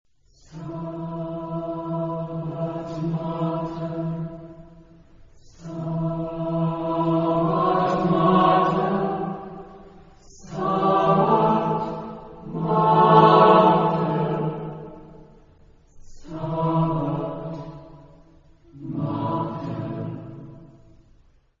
Epoque: 20th century
Genre-Style-Form: Motet ; Sacred
Type of Choir: SSAATTBB  (8 mixed voices )
Tonality: free tonality